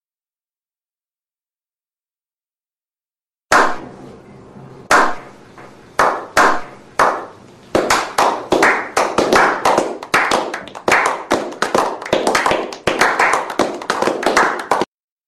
Sound Effects
Sarcastic Clap